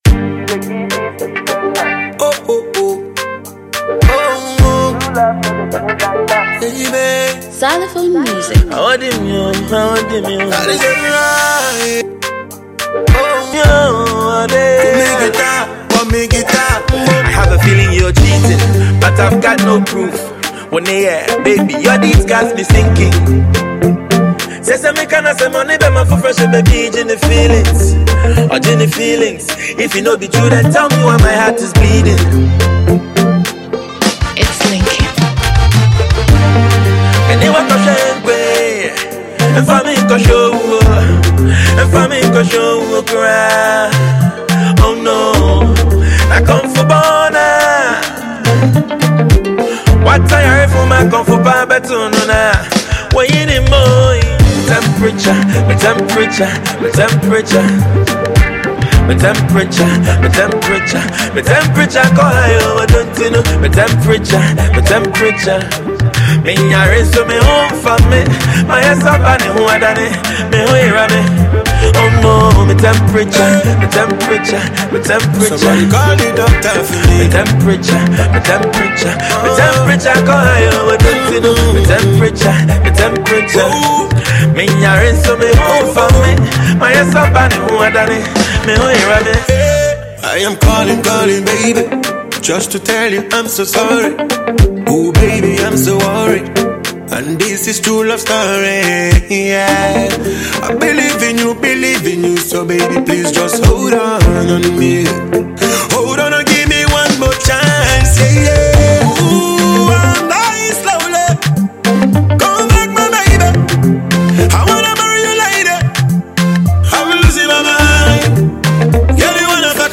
high life
dancehall